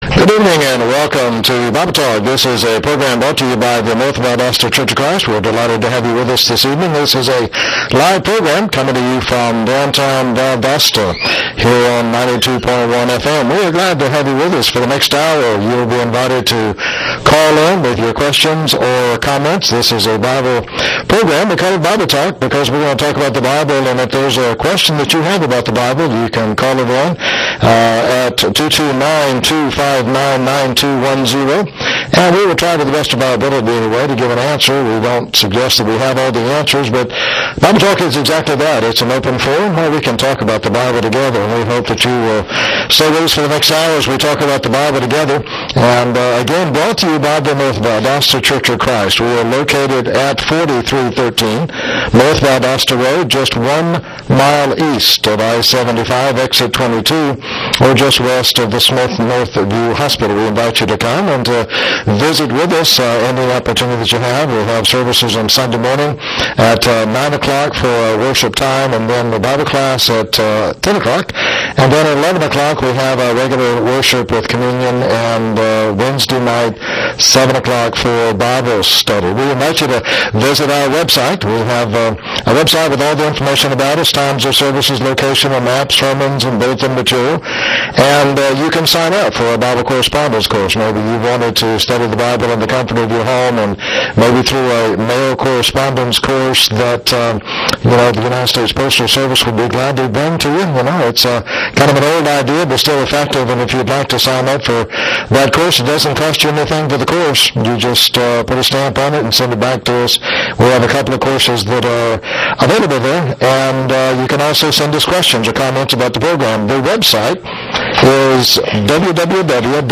Guest panelist